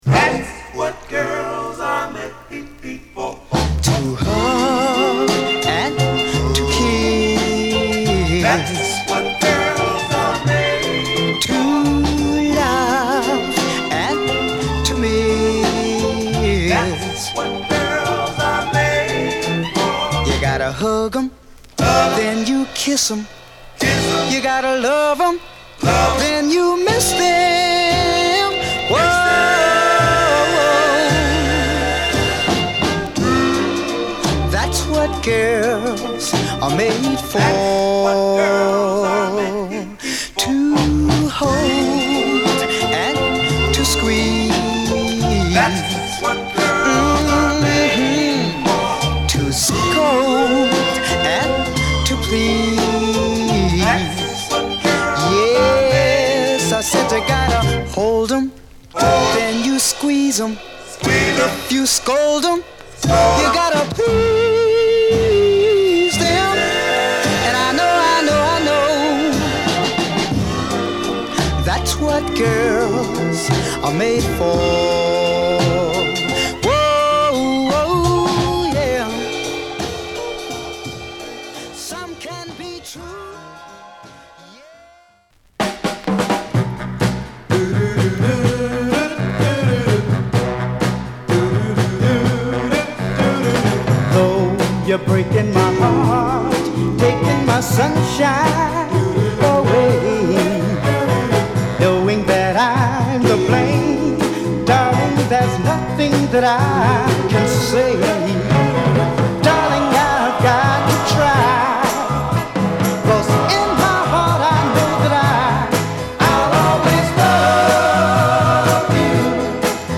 コーラスワークの冴えたR&BバラードA1
弾んだリズムに素晴らしいヴォーカル／コーラスが乗るノーザンチューンA2
期待を裏切らないモータウンサウンド／ノーザンソウルを披露！！